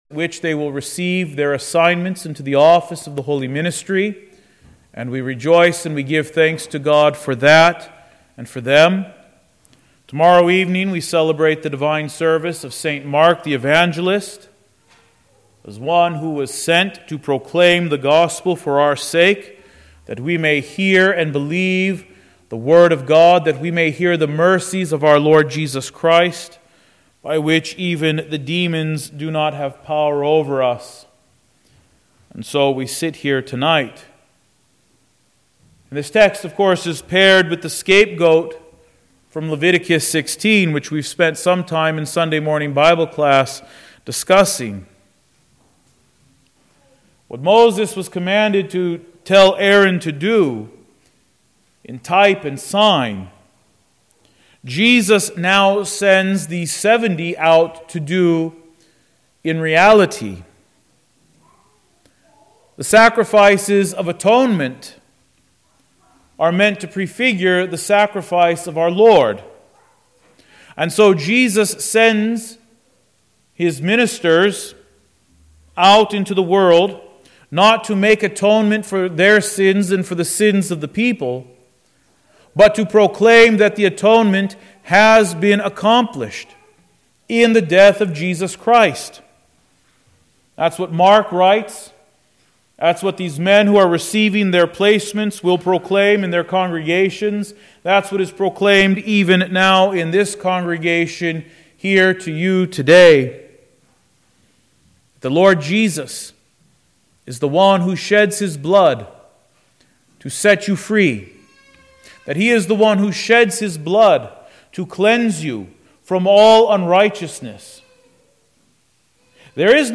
Wednesday, April 24, 2024 (Evening Prayer) - Sermon